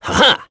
Esker-Vox_Attack3.wav